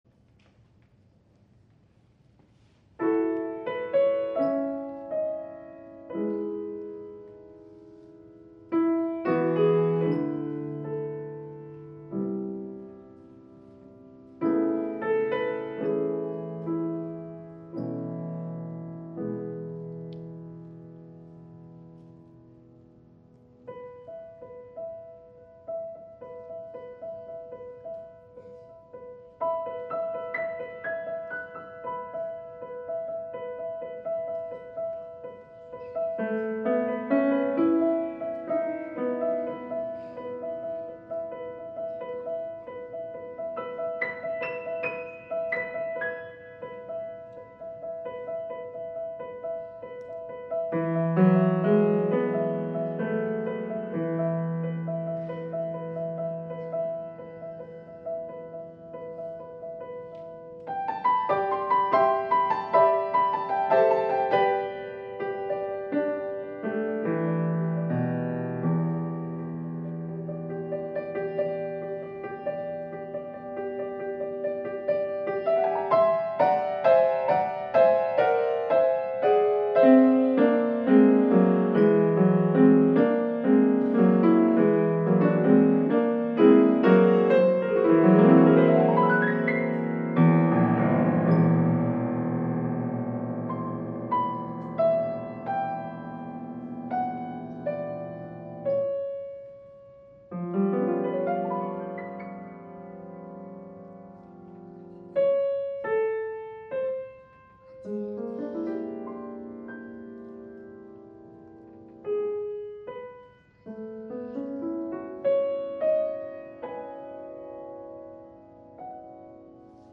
4-частная сюита для фортепиано:
Импрессионизм
Запись произведена на концерте по композиции